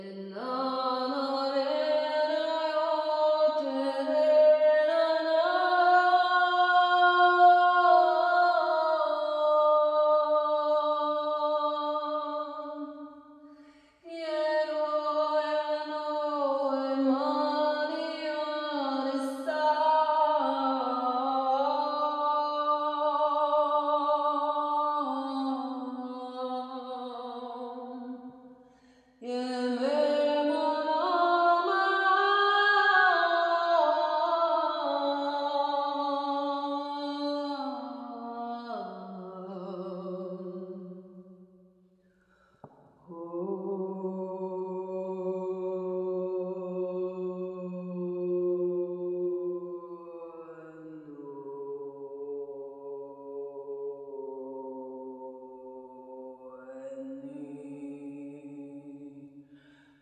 A Capella                    Durée 06:43